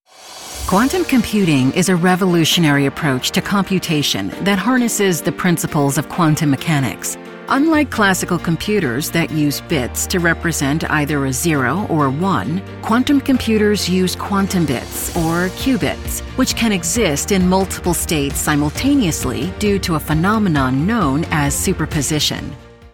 Explainer & Whiteboard Video Voice Overs
Adult (30-50) | Yng Adult (18-29)